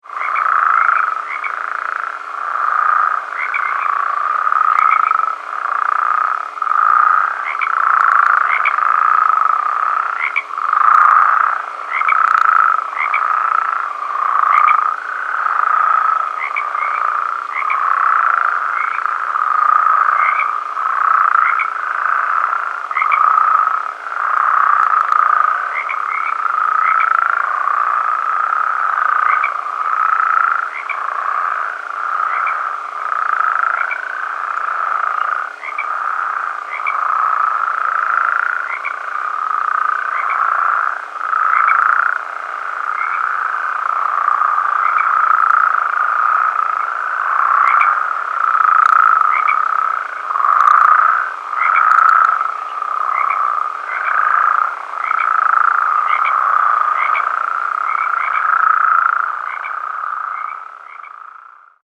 The call of the Western Spadefoot is a short loud trill, like a quick snore, lasting less than one second.
The following recordings were made at night in mid March at a distance of 200 - 300 ft. from a creek flowing through Alameda and San Joaquin Counties. Sierran Treefrogs are heard in the background.
Sound  This is a 61 second recording the advertisement calls of a large group of Western Spadefoots made in San Joaquin County.